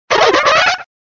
Fichier:Cri 0418 DP.ogg
contributions)Televersement cris 4G.